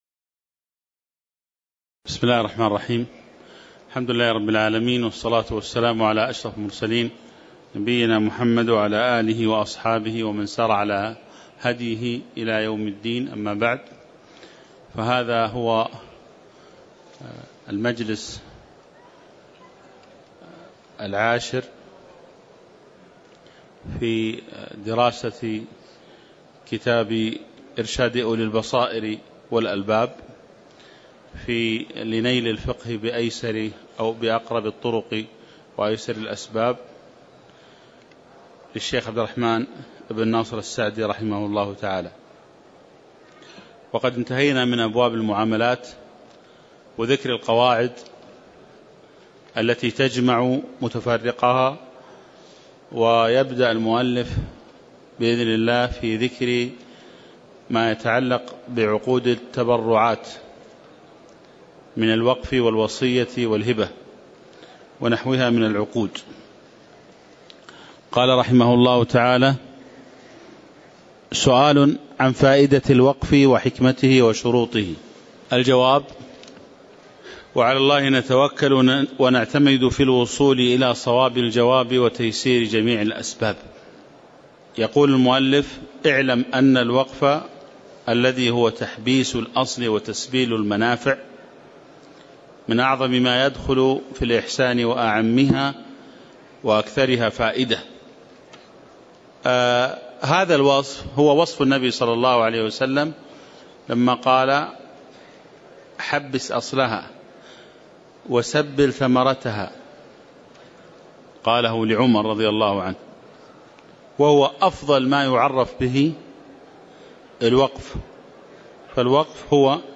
تاريخ النشر ٢٠ شوال ١٤٣٨ هـ المكان: المسجد النبوي الشيخ